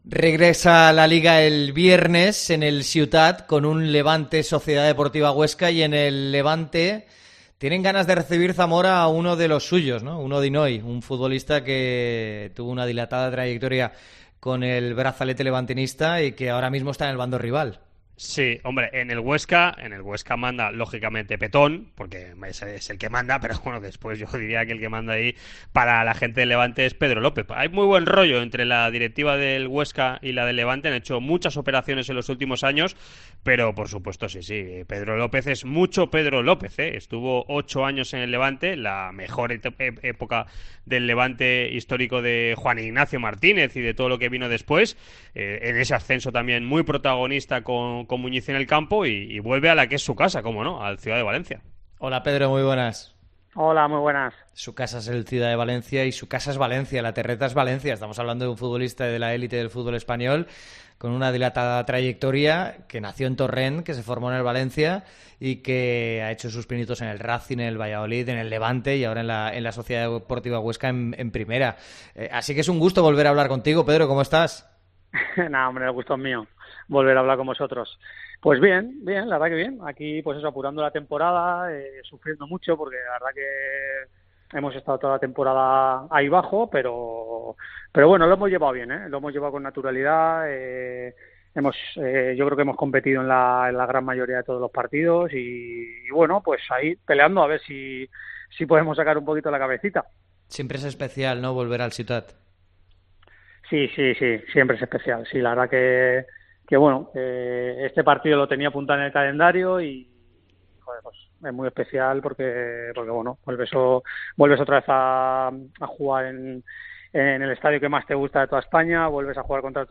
AUDIO. Entrevista a Pedro López en Deportes COPE Valencia